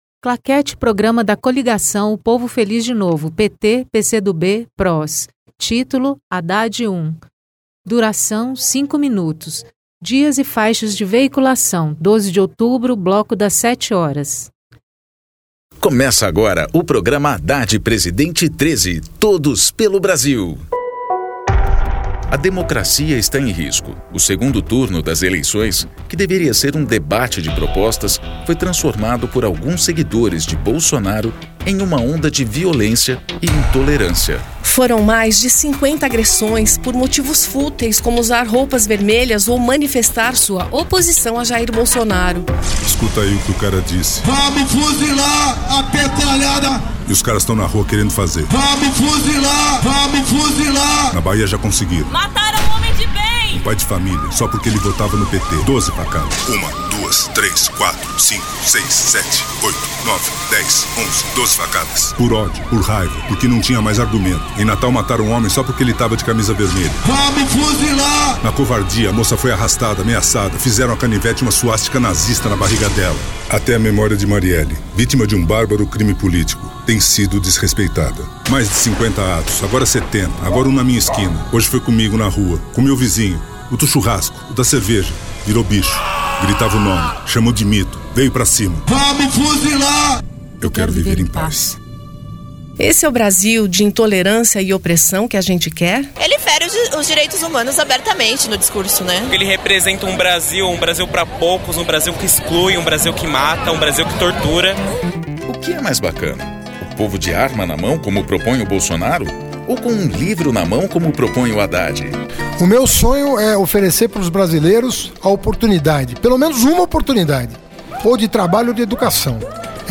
TítuloPrograma de rádio da campanha de 2018 (edição 31)
Gênero documentaldocumento sonoro